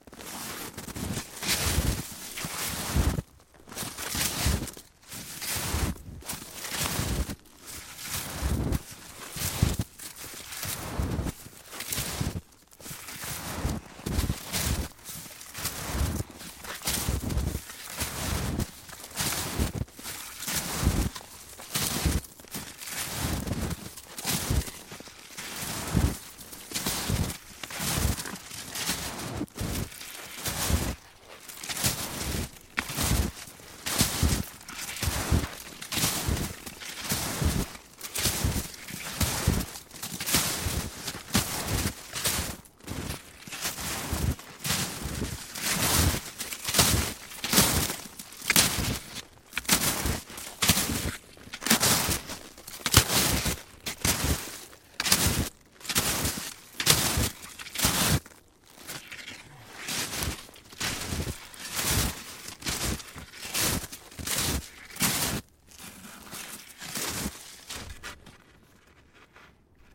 冬天" 脚步声沉重的橡胶靴听起来有点像雪鞋的声音，半包的雪地上向后走着，发出唰唰的慢中快的声音。
描述：脚步声沉重的橡胶靴声音有点像雪鞋半成品雪向后走向嗖嗖慢速中速快速停止开始scuffs.flac
标签： 脚步 雪鞋 橡胶 靴子
声道立体声